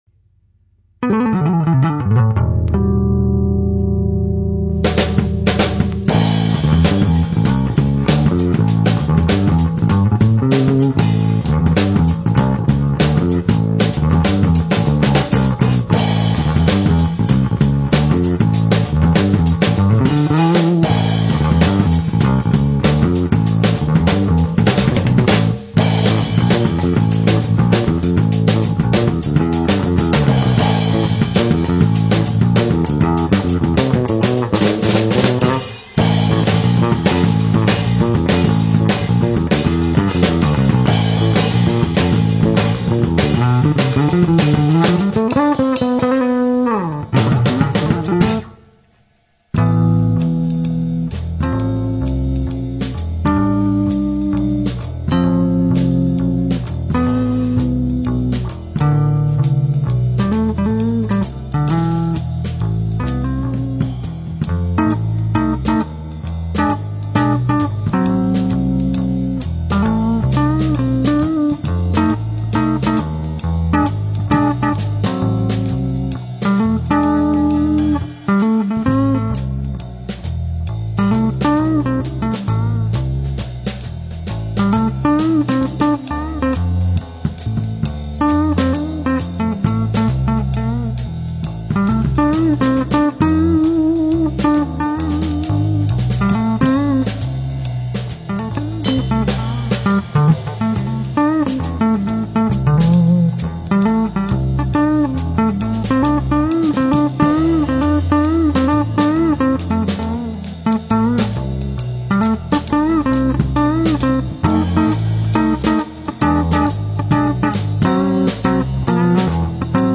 БАС-ГИТАРА